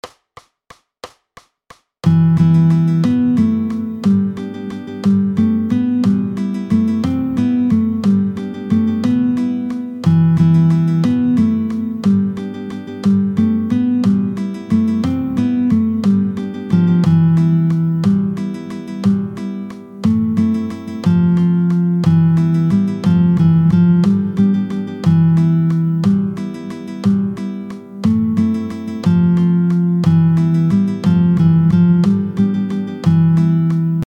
√ برای ساز گیتار | سطح آسان
همراه 3 فایل صوتی برای تمرین هنرجویان